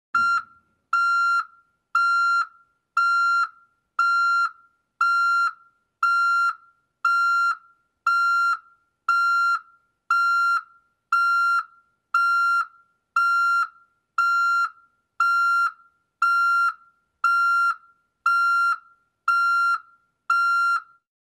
Звук сигнала заднего хода автомобиля писк
Писк сигнала заднего хода у машины
Пищащий звук при включении задней передачи